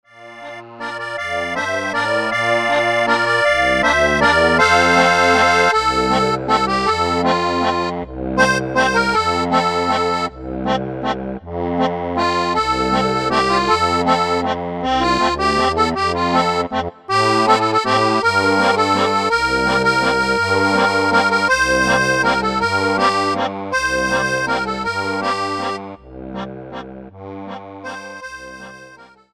Acordeón
🎼 Tonalidad: F 📏 Formato: PDF de alta calidad (Tamaño A4).